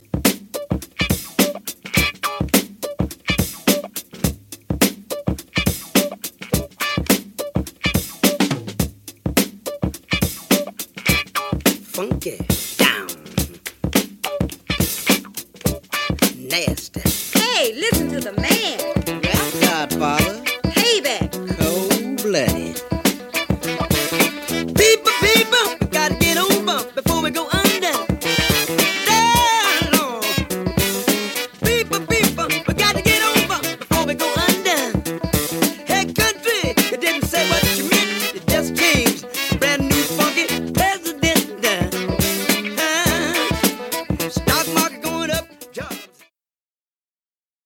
Extended break versions.
extended Breaks Special edition